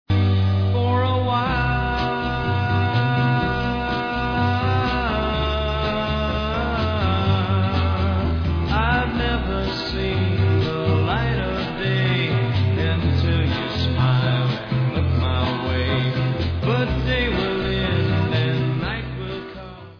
Sixties psychedelia, could be on the "nuggets" box